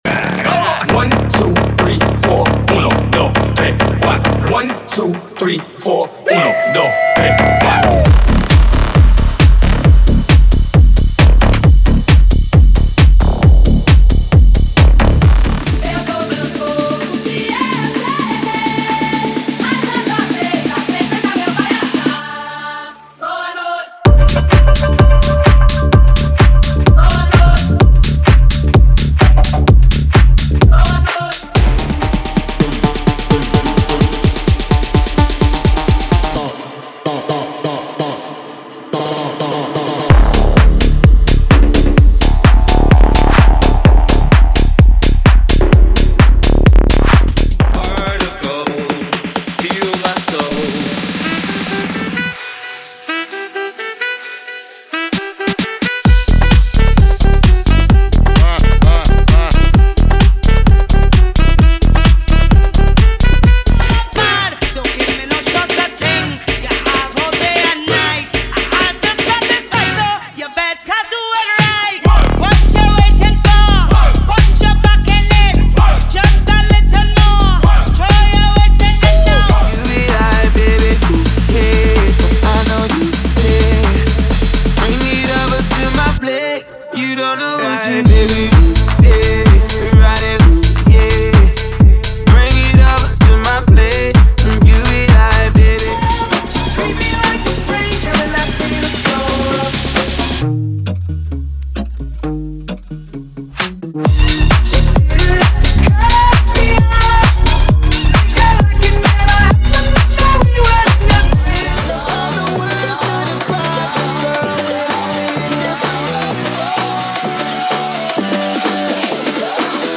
Os Melhores Tech House do momento estão aqui!!!
• Versões Extended
• Sem Vinhetas